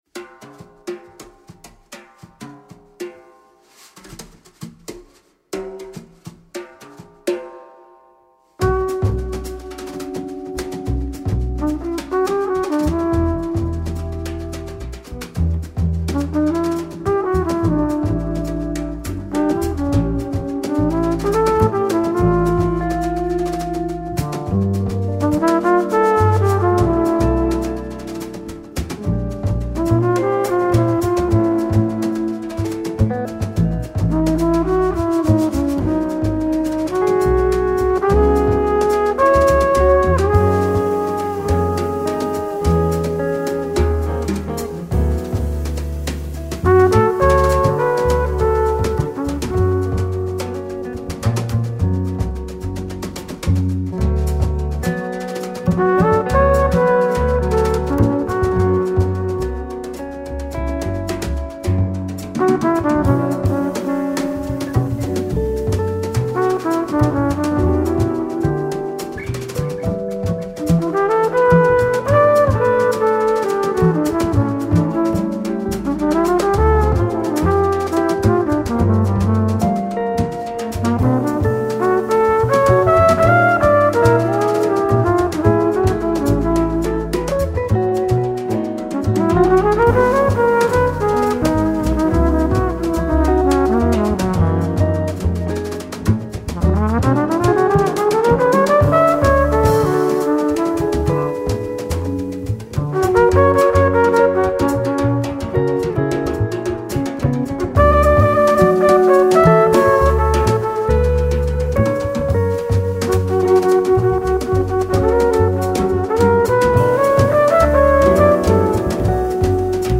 Modern Jazz.
wonderfully sublime autumnal album
Flugelhornist